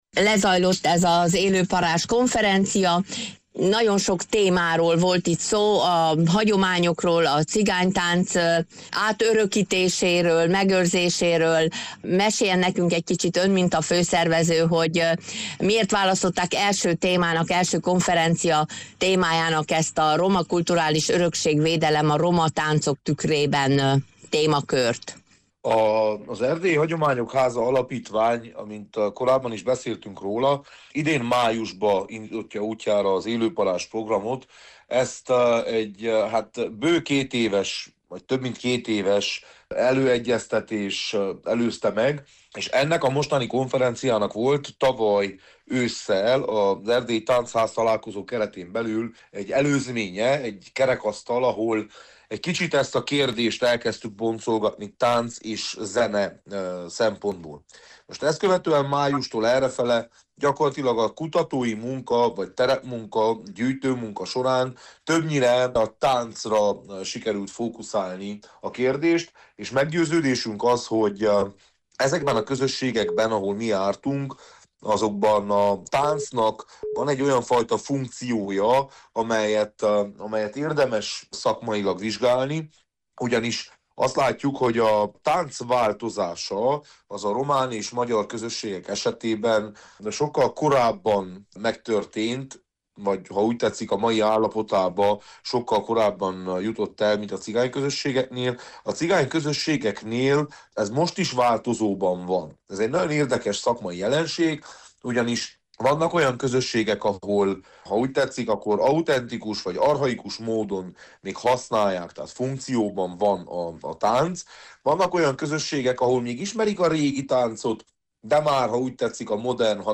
Az Élő Parázs Program Facebook oldalán értesültünk minderről, és felhívtuk András Lóránd szociológust, Hargita megyei tanácsost, a program felelősét, hogy számoljon be nekünk az eseményről.